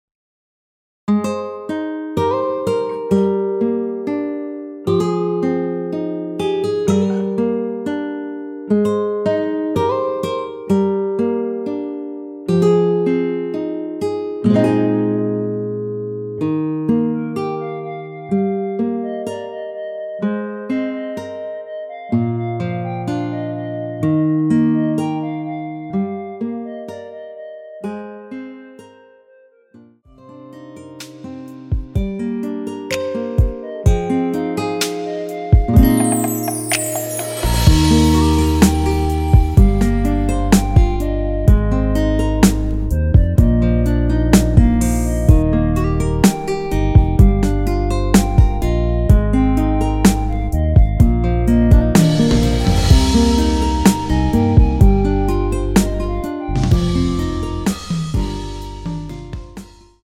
원키에서(+3)올린 멜로디 포함된 MR입니다.
Eb
앞부분30초, 뒷부분30초씩 편집해서 올려 드리고 있습니다.
중간에 음이 끈어지고 다시 나오는 이유는